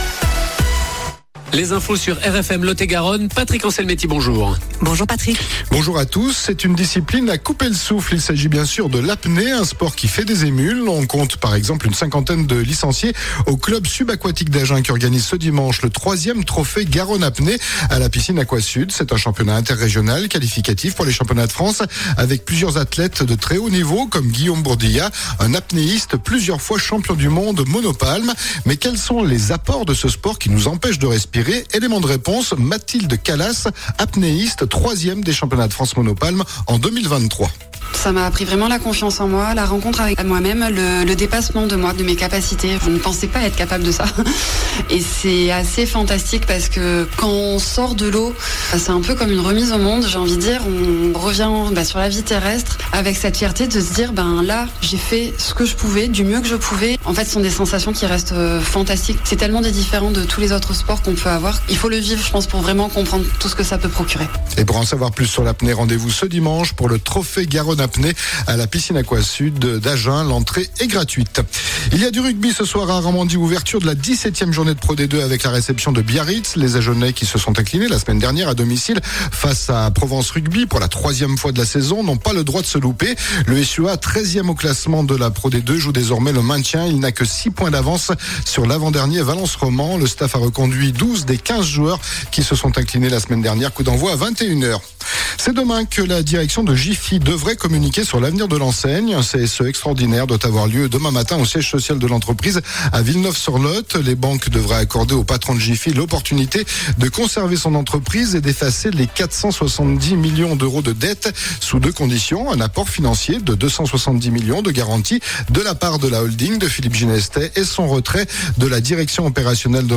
Interview RFM 16/01/2025